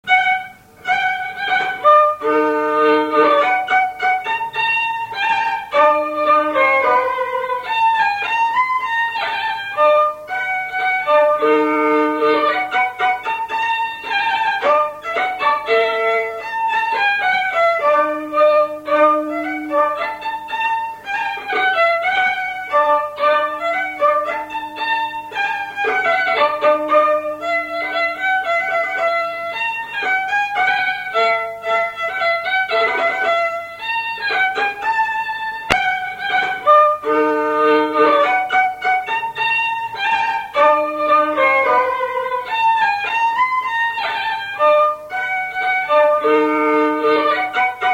polka / marche
instrumental
Pièce musicale inédite